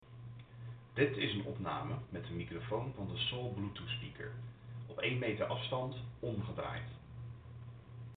Bij de eerste opname is de voorkant van de Soul naar mij gericht en bij de tweede opname is de achterkant naar mij gericht.
Om er direct in te duiken: de kwaliteit is logischerwijs niet denderend.
Het lijkt er niet op dat de microfoon unidirectioneel is, want de kwaliteit en het volume gaan ietwat achteruit in de tweede opname.
Microfoonopname-Fresh-n-Rebel-Soul-achterkant.mp3